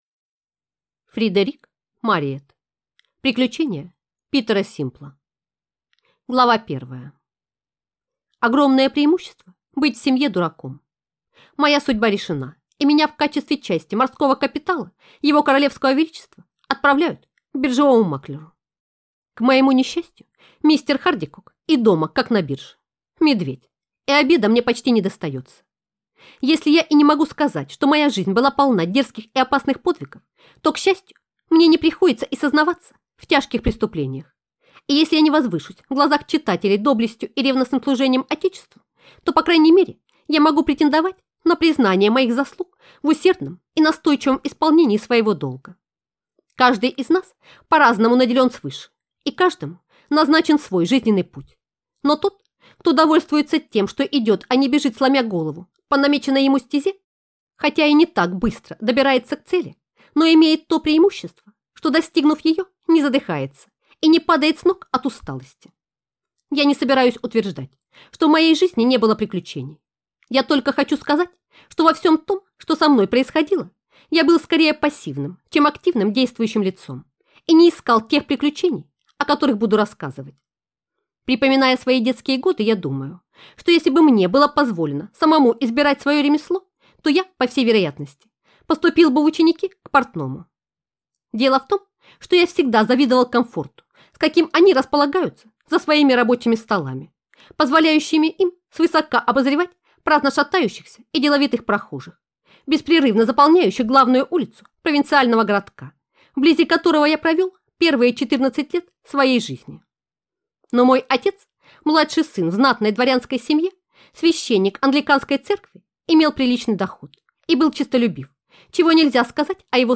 Аудиокнига Приключение Питера Симпла | Библиотека аудиокниг